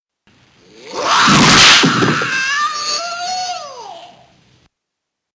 آهنگ زنگ فریاد بروسلی